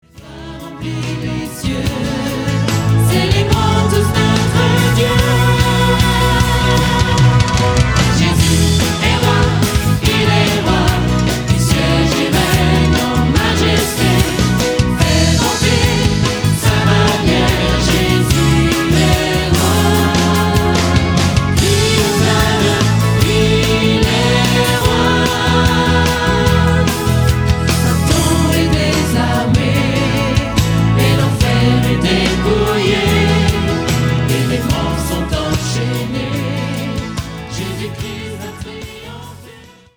des chants dynamiques enregistrés en public.